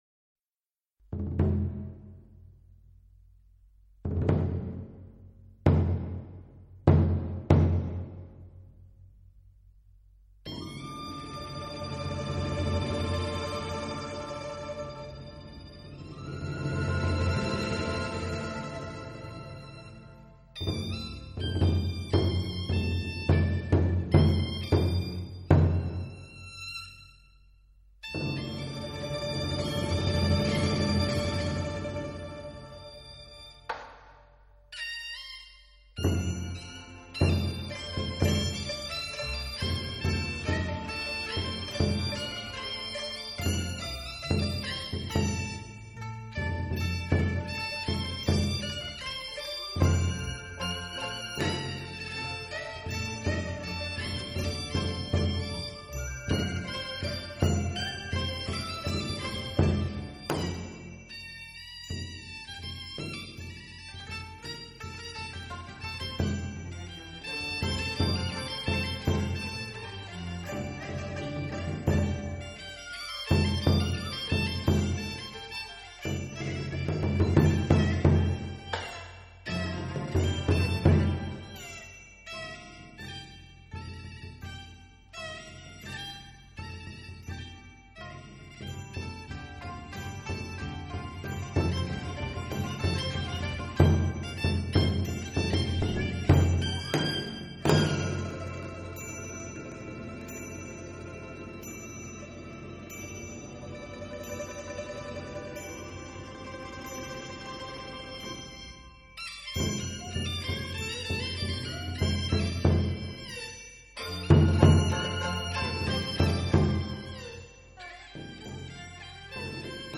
它的演出形成独特，由京胡演奏，以击鼓相伴。